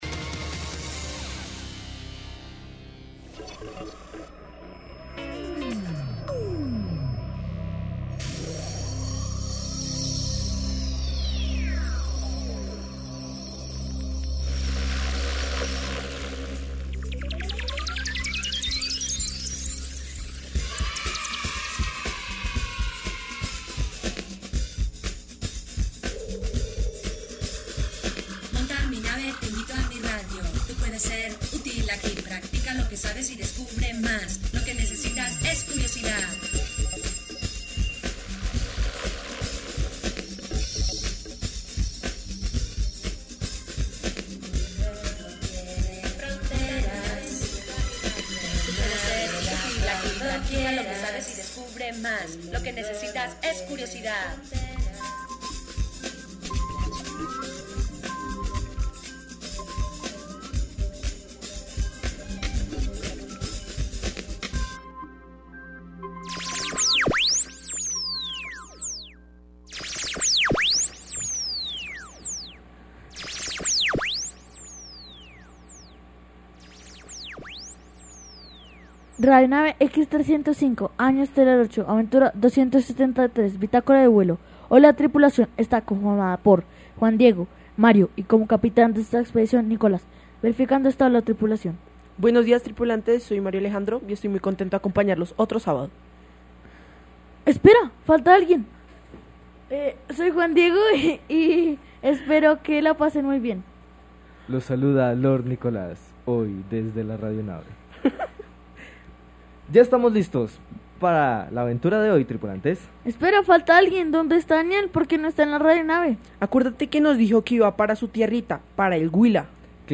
In the vibrant atmosphere of Neiva and Huila, the June festivities, specifically San Pedro, are an event experienced with great enthusiasm and anticipation from the beginning of the year. The "Tripulantes" radio station captures the essence of these celebrations through sounds and stories that describe symphonic band gatherings, alboradas (a traditional dance), and craft fairs, elements that invade every corner of the town and its farms.